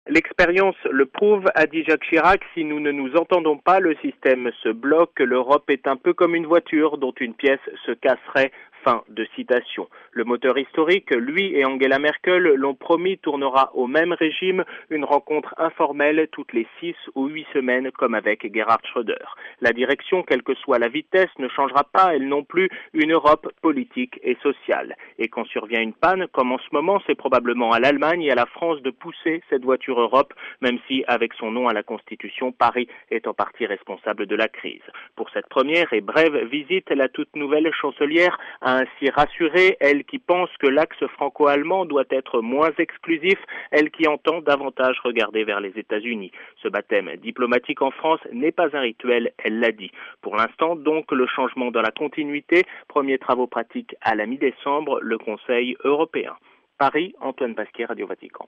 A Paris, la correspondance d'